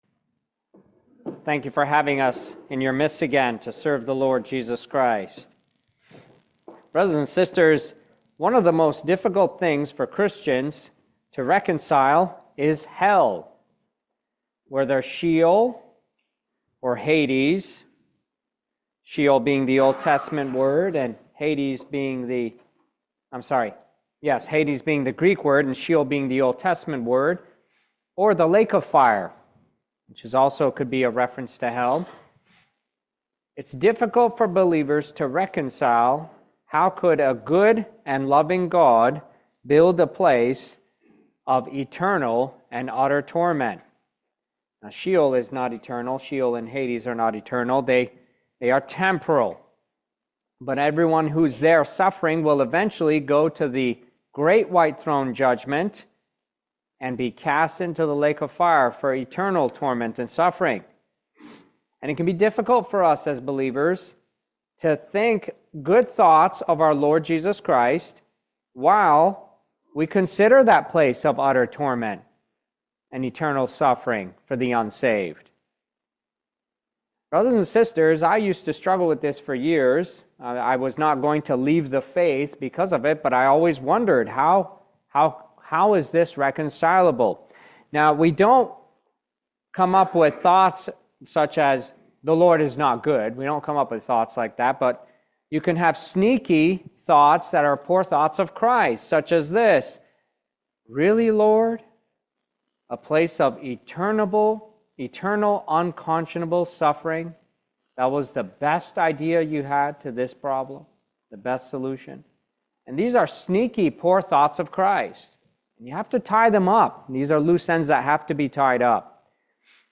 Luke 16:19-31 Service Type: Family Bible Hour The Rich Man represents the unsaved and their destined to Hell